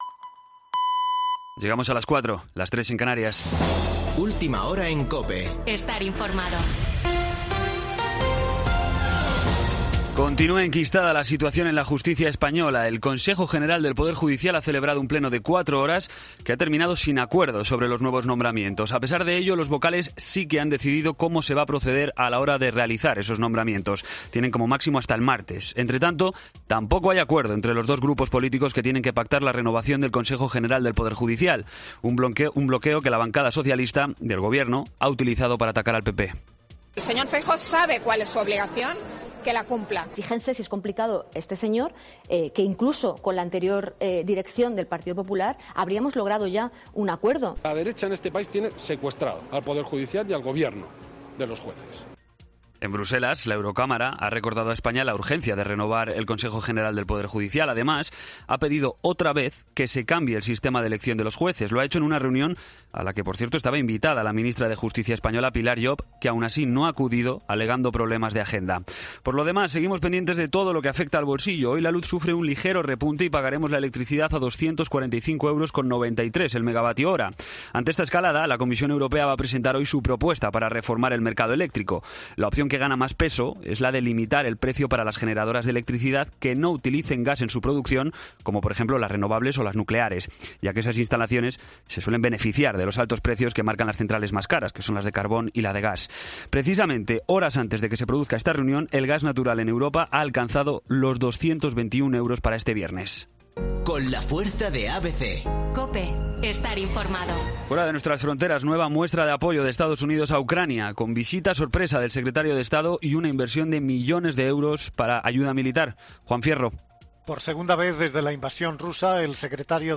Boletín de noticias COPE del 9 de septiembre a las 04:00 horas